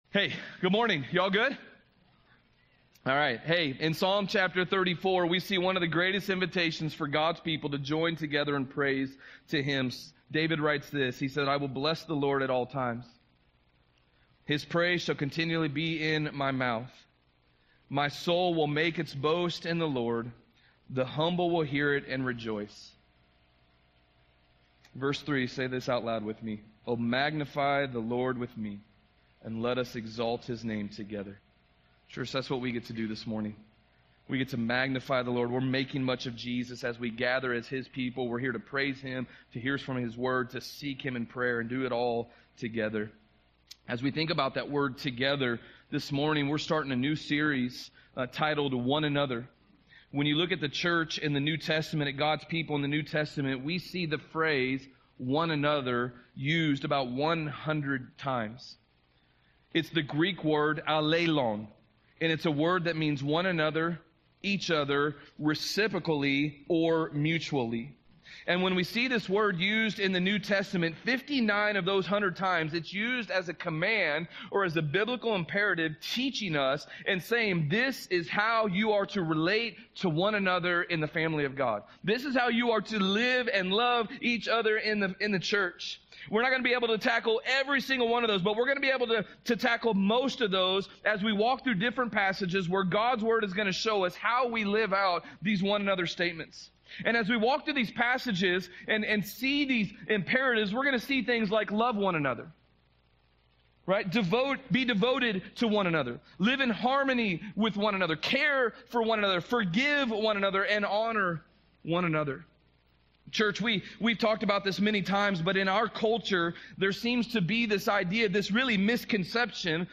Sermon+10.6.24.mp3